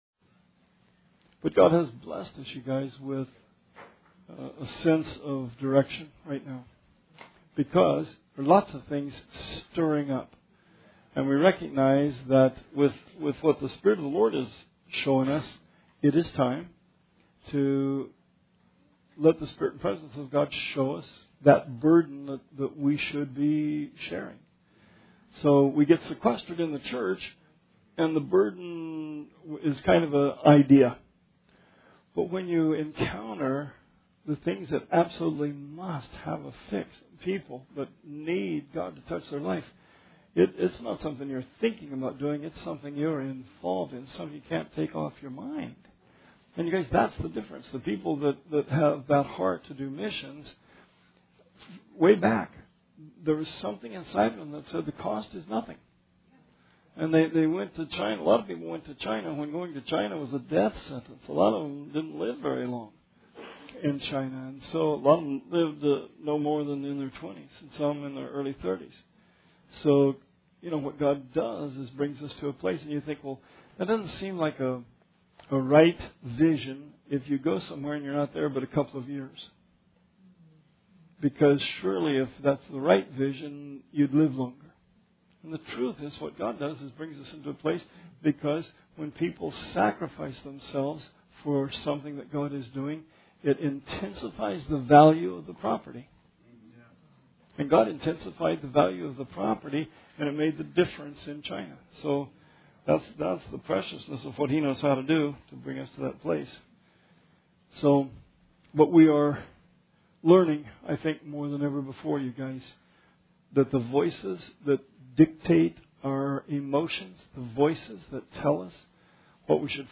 Bible Study 8/23/17